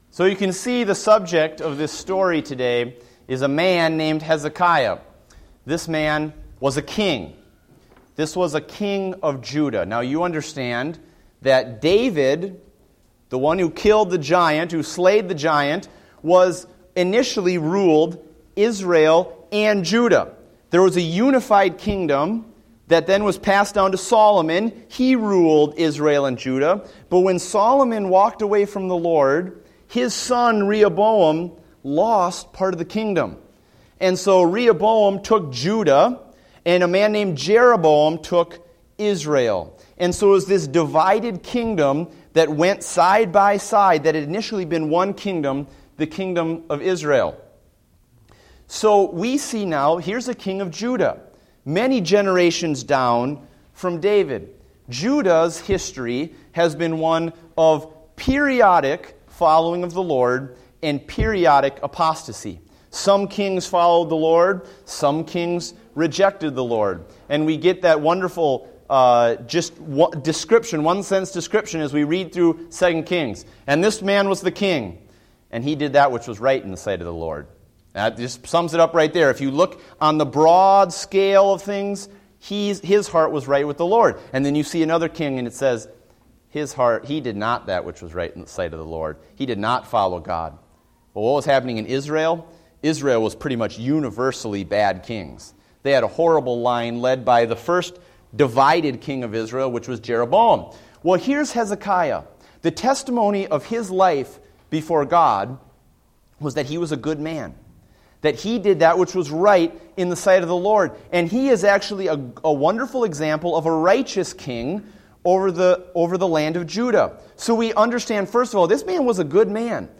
Date: April 20, 2014 (Adult Sunday School)